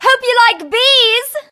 bea_ulti_vo_04.ogg